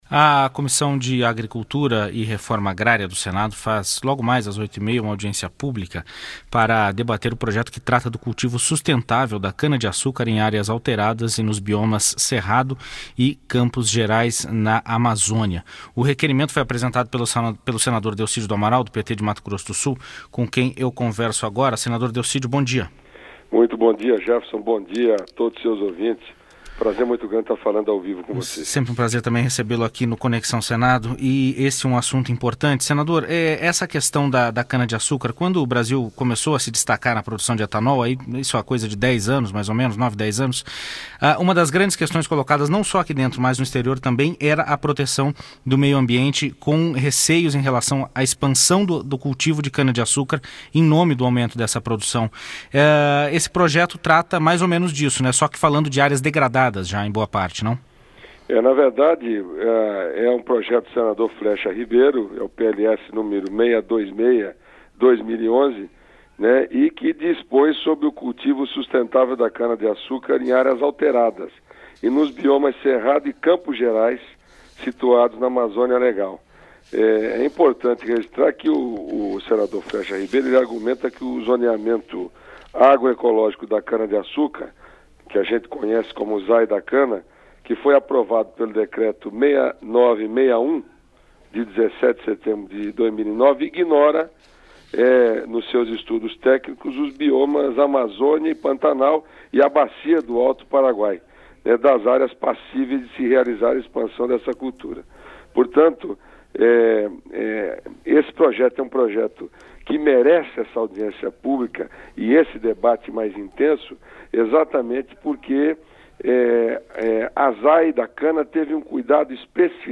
Entrevista com o senador Delcídio do Amaral (PT-MS), autor do requerimento para audiência pública.